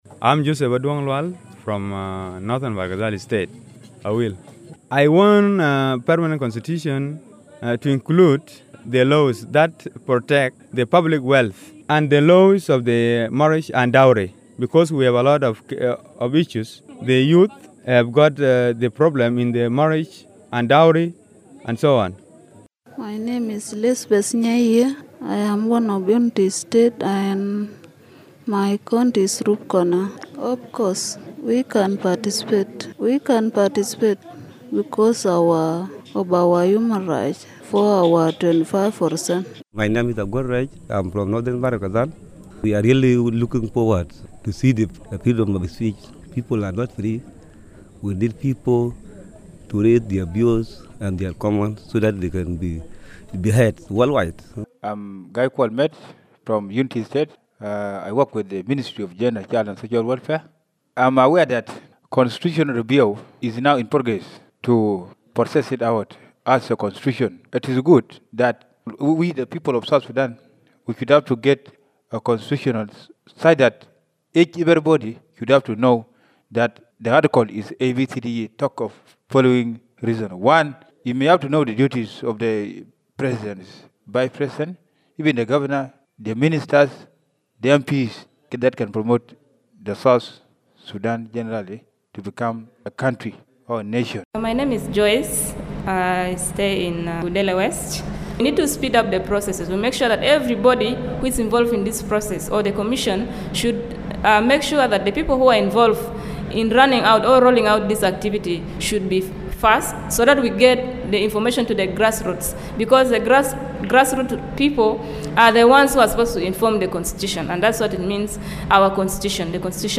Constitution Review VoX Pops